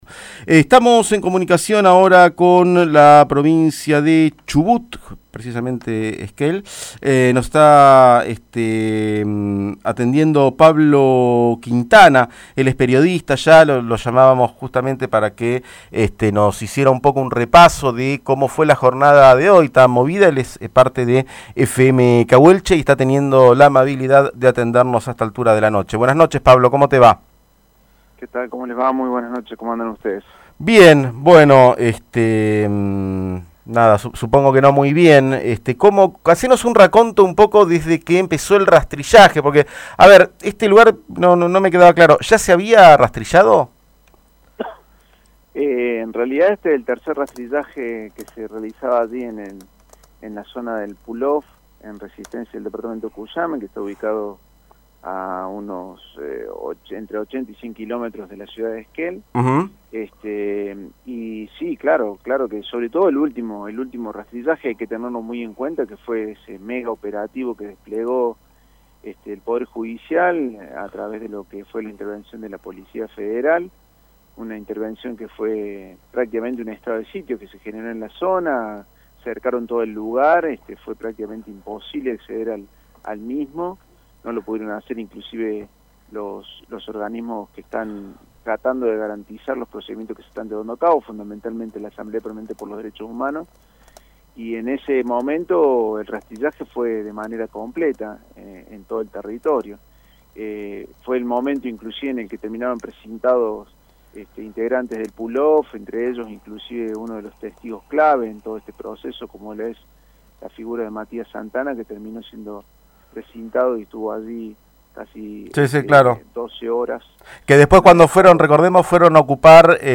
En diálogo con Catarsis Segunda Parte